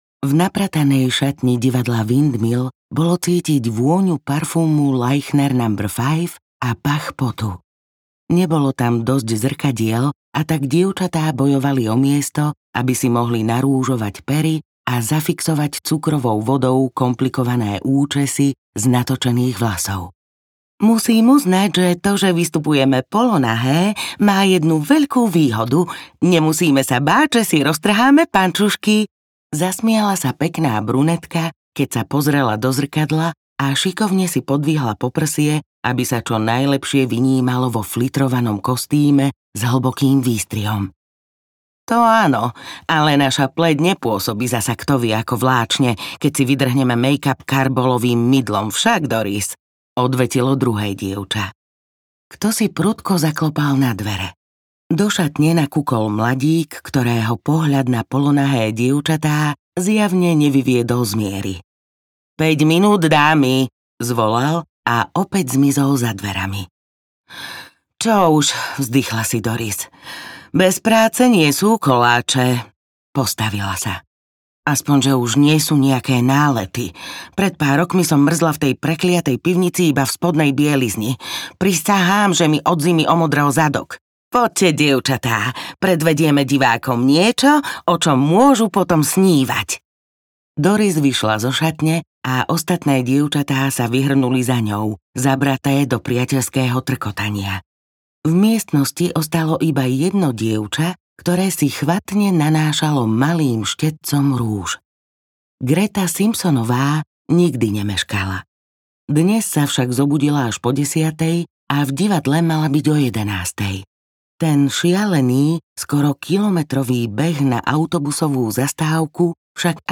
Anjelský strom audiokniha
Ukázka z knihy